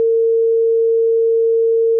455.4 Hz, well over a half-tone higher.[5]
Tone_455.4Hz.ogg.mp3